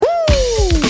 Will Smith Whoo!